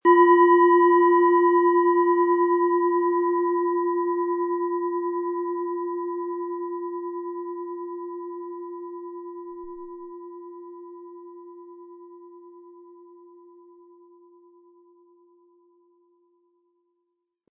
Wasser
Harmonische Töne erhalten Sie, wenn Sie die Schale mit dem kostenfrei beigelegten Klöppel ganz sanft anspielen.
MaterialBronze